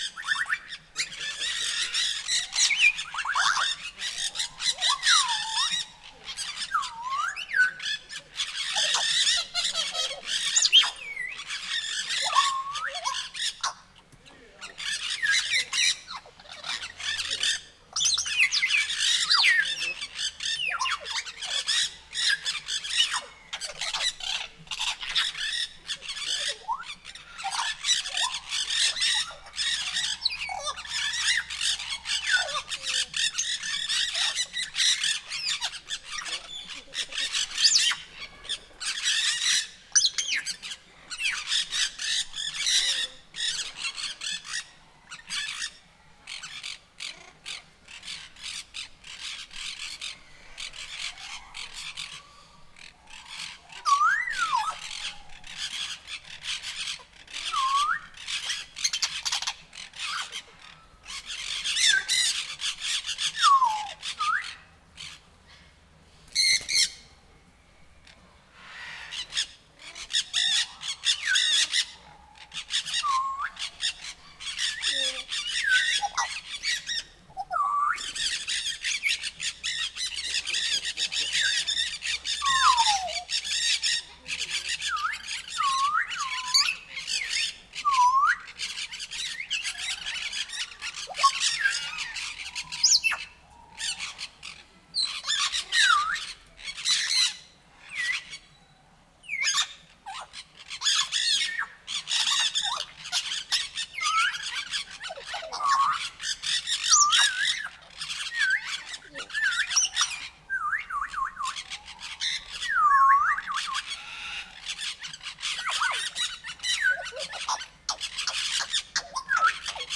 Directory Listing of /_MP3/allathangok/nyiregyhazizoo2019_professzionalis/jako_papagaj/
jobbrolmasikpapagaj_nyiregyhaza0203.WAV